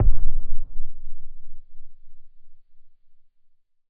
explosion_far_distant_05.wav